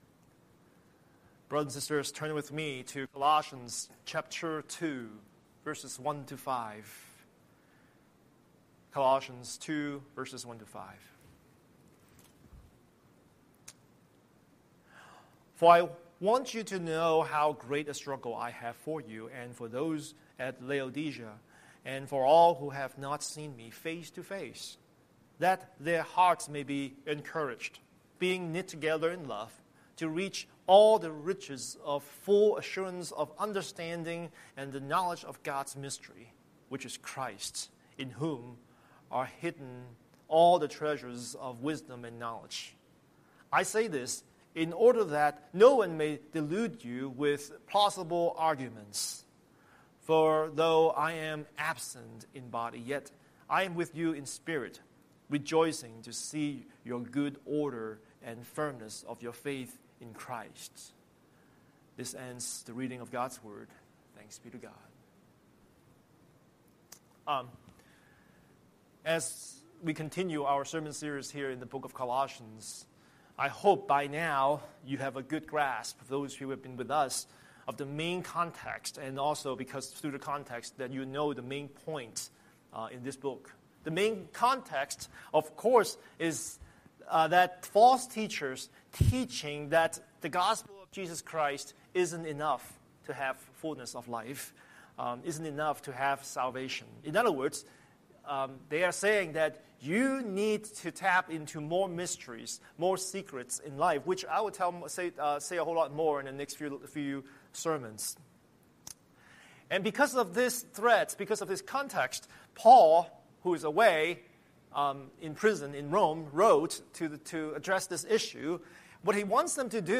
Scripture: Colossians 2:1-5 Series: Sunday Sermon